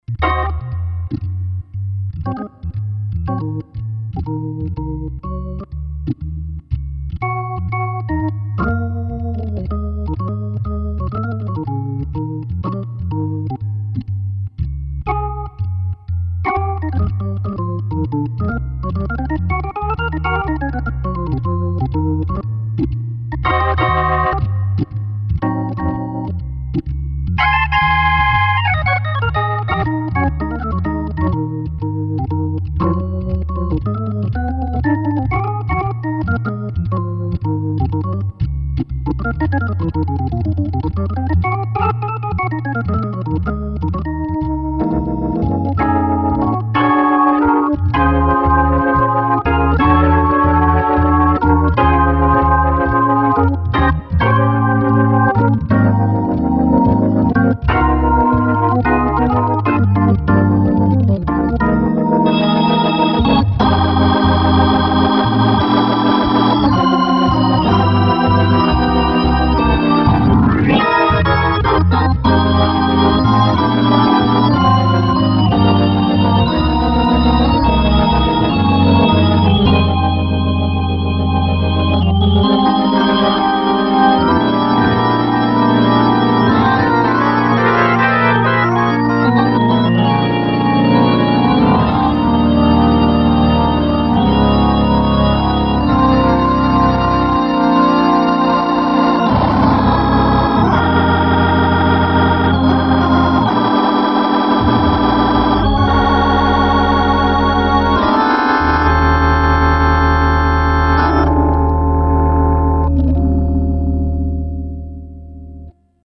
Sympa petit (et court) blues.
Qui swingue.
Et j'aime bien les accords de la fin...
Ben sinon, l'overdrive est plutôt léger (pour moi, héhéhé) et puis, ça passe plutôt bien et ça ne me casse pas les oreilles.
moi j'aime bien. plus d'overdrive nuirait au résultat final....
L'overdrive, dans mon cas ne me dérange absolument pas, il est très subtile, juste ce qu'il faut.